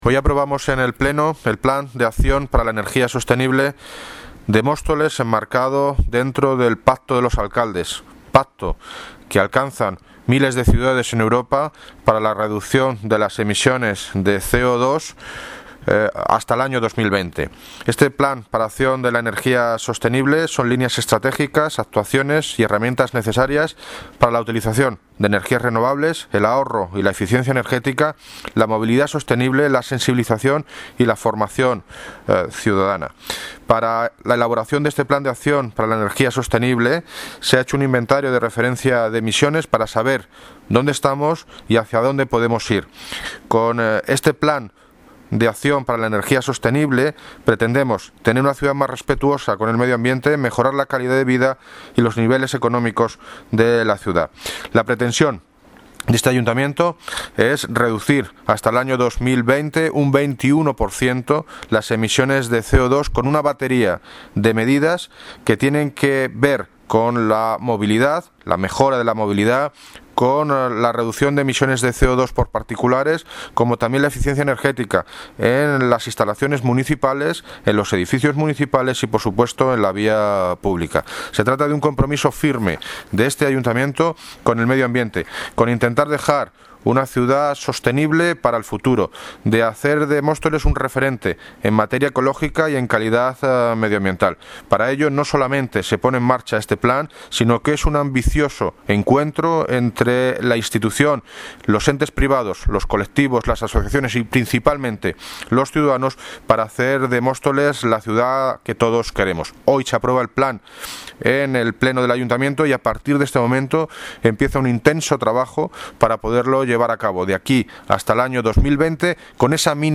Audio - David Lucas (Alcalde de Móstoles) Sobre pacto de los alcaldes energía sostenible